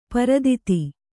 ♪ paraditi